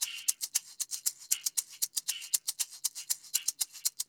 AQF SHAKER.wav